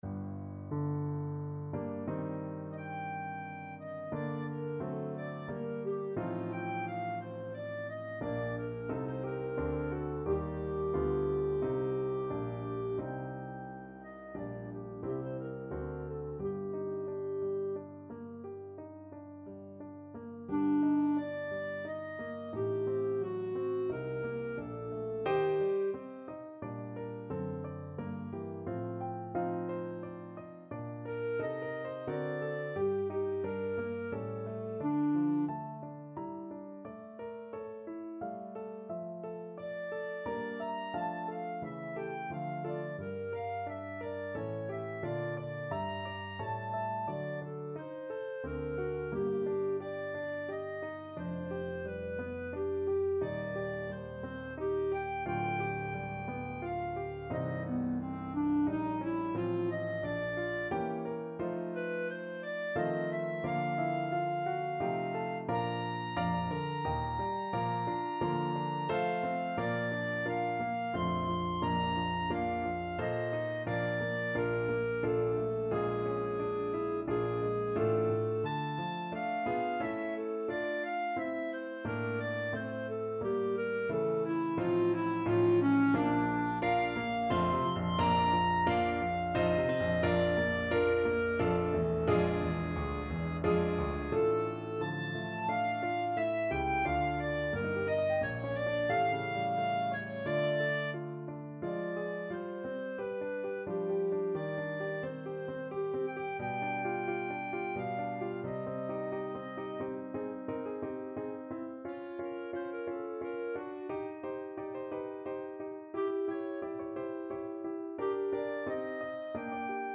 3/4 (View more 3/4 Music)
=88 Nicht schnell =100
Classical (View more Classical Clarinet Music)